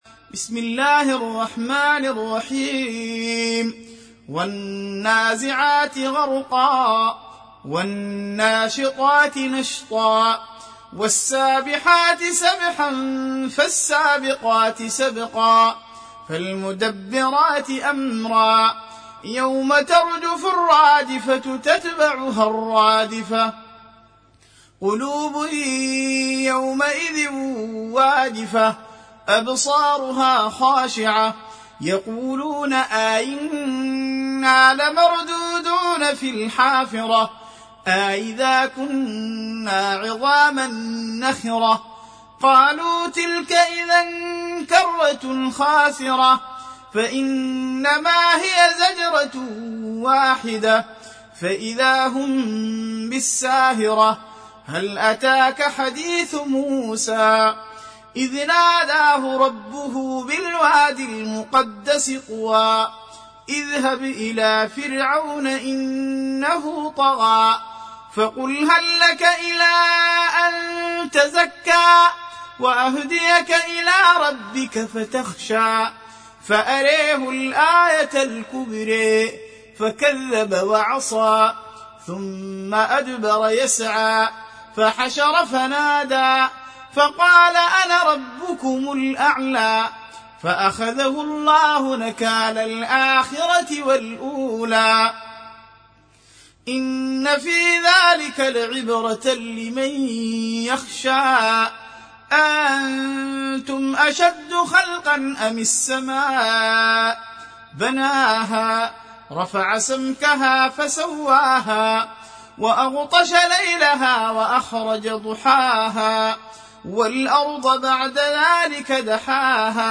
Surah Sequence تتابع السورة Download Surah حمّل السورة Reciting Murattalah Audio for 79. Surah An-Nazi'�t سورة النازعات N.B *Surah Includes Al-Basmalah Reciters Sequents تتابع التلاوات Reciters Repeats تكرار التلاوات